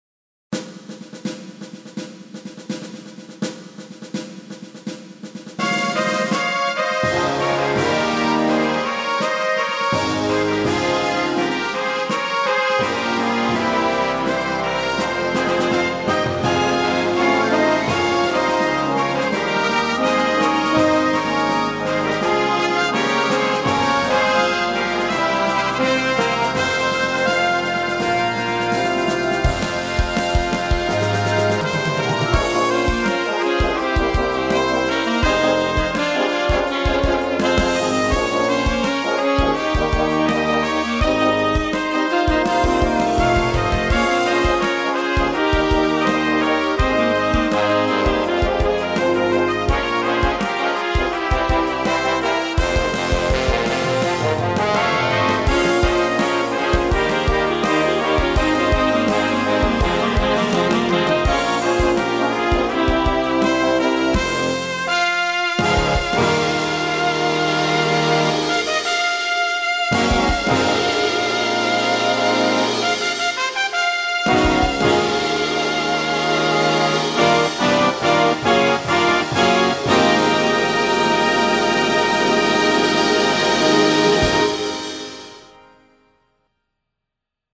для духового оркестра
MIDI DEMO.